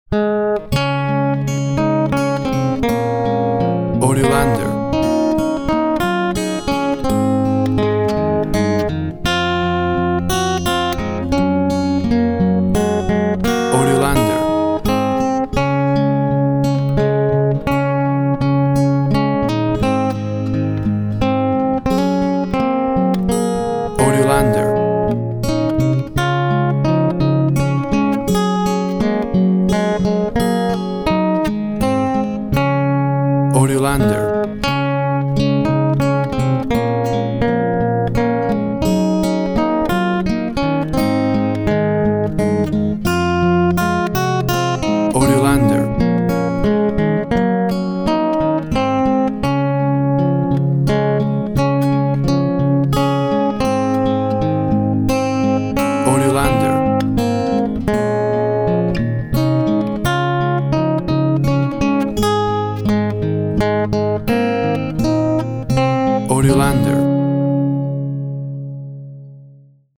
A traditional acoustic guitar version
Tempo (BPM) 90